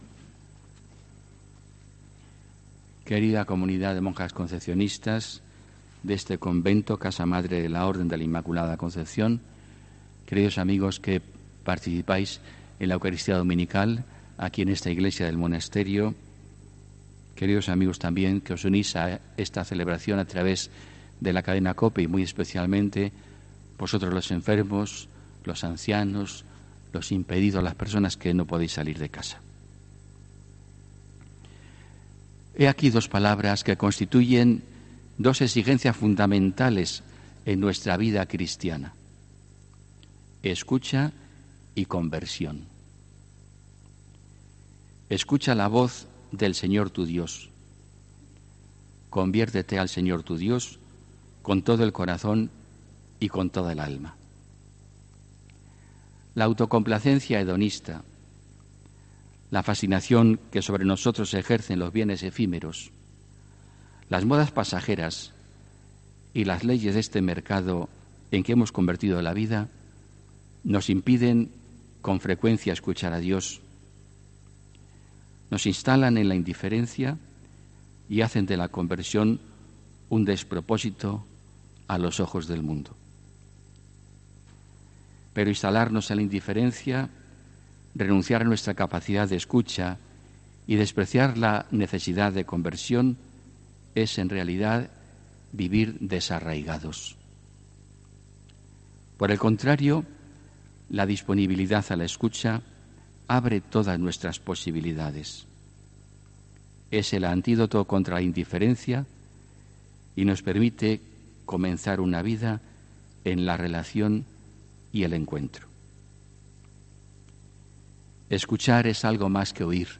Homilía